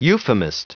Prononciation du mot euphemist en anglais (fichier audio)
Prononciation du mot : euphemist